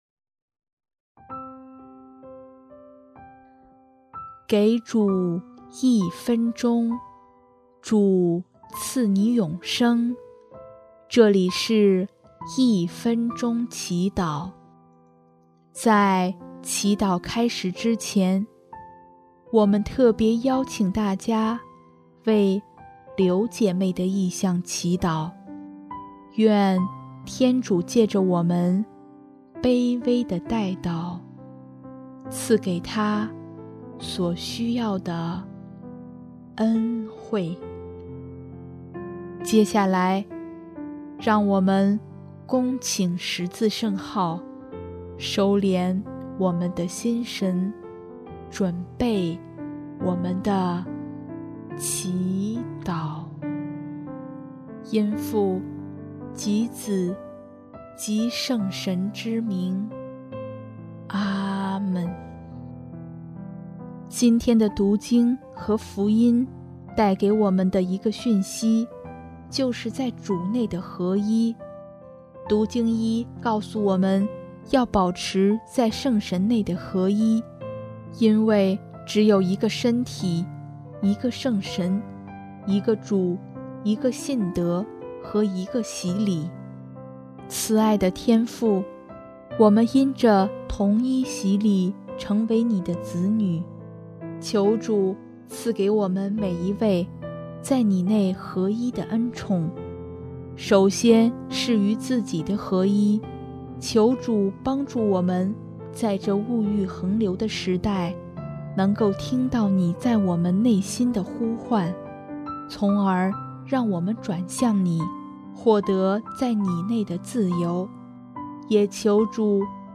音乐： 主日赞歌